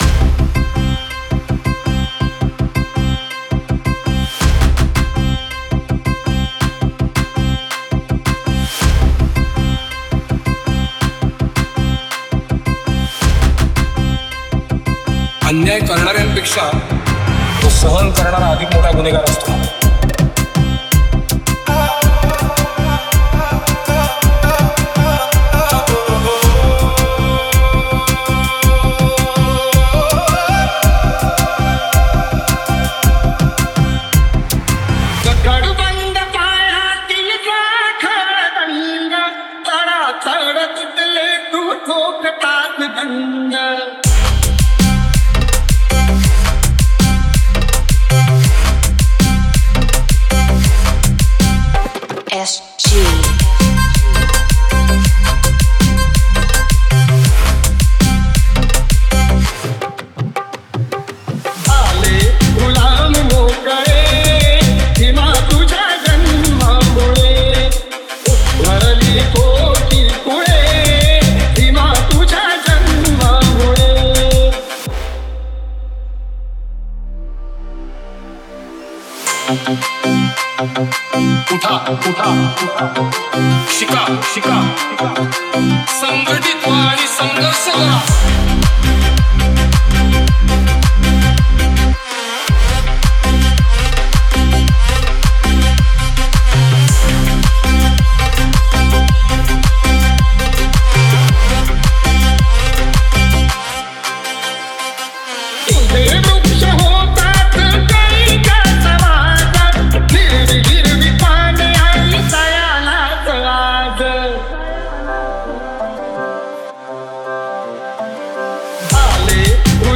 Category: MARATHI DJ